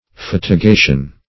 Search Result for " fatigation" : The Collaborative International Dictionary of English v.0.48: Fatigation \Fat`i*ga"tion\, n. [L. fatigatio: cf. OF. fatigation.]
fatigation.mp3